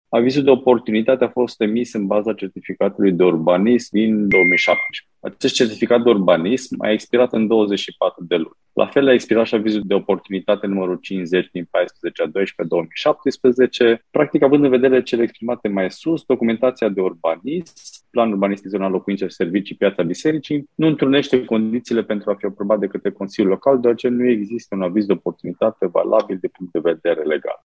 Consilierul local Răzvan Negrișanu, care este și arhitect, a explicat de ce PUZ-ul nu poate fi aprobat.